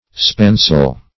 Spancel \Span"cel\, n. [Perhaps span + AS. s[=a]l a rope.]